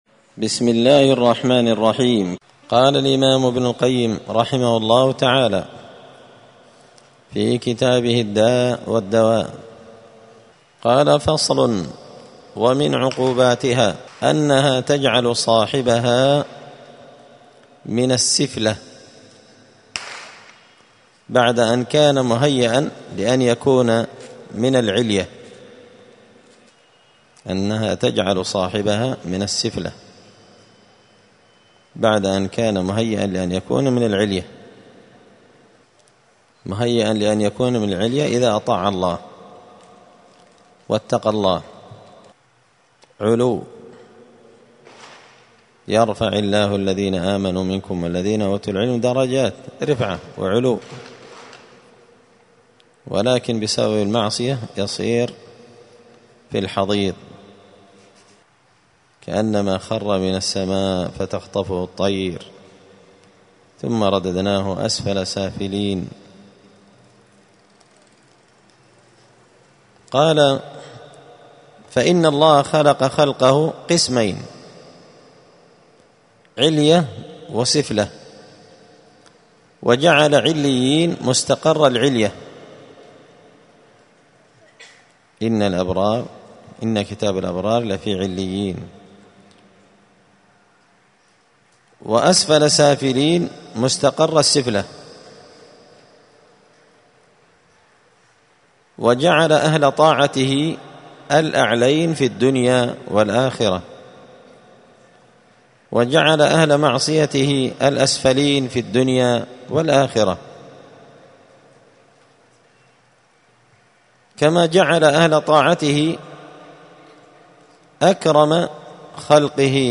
دار الحديث السلفية بمسجد الفرقان بقشن المهرة اليمن 📌الدروس الأسبوعية